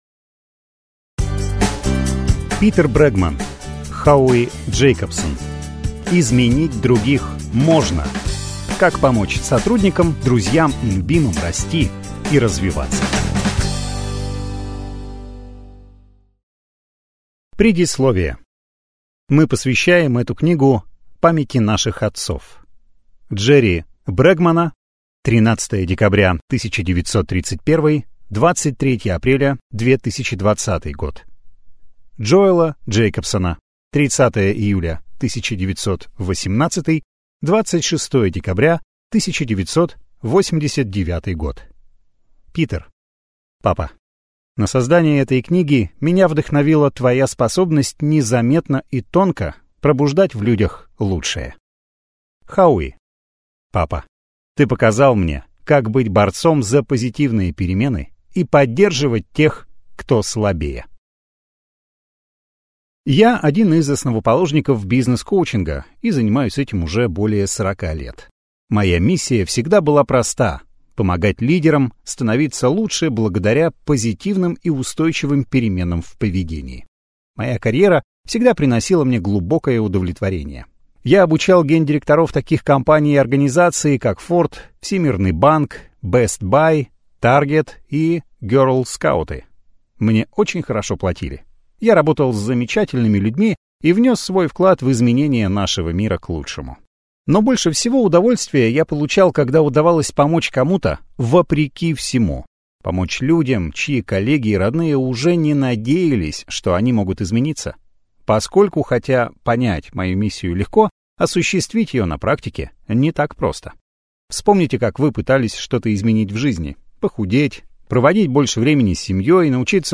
Аудиокнига Изменить других можно! Как помочь сотрудникам, друзьям и любимым расти и развиваться | Библиотека аудиокниг